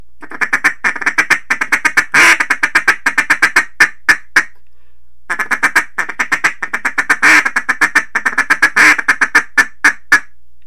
Duck Calling - Feeding Chuckle
The Single Cut Chuckle, as the name implies, is done with a single cut of the air stream utilizing the tip of the tongue. Here the tip of the tongue alone is used to gate the stream of air on and off.